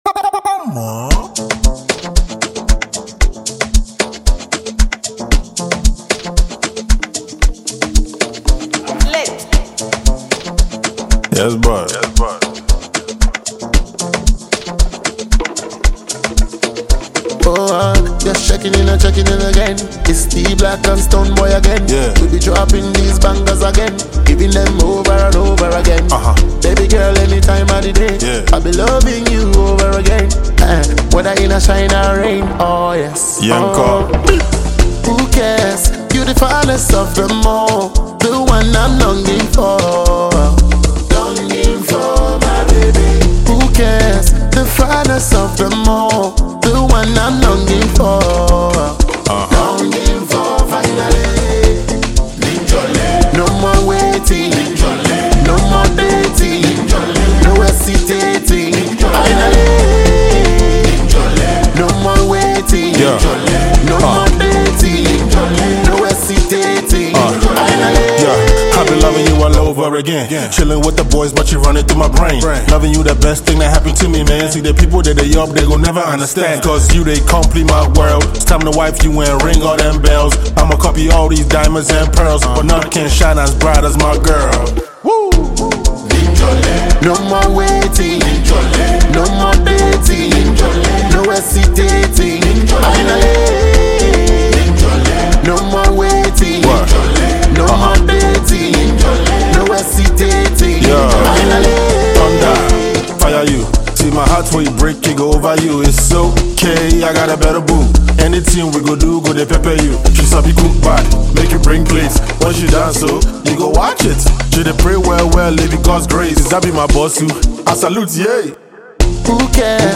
• Genre: Afrobeats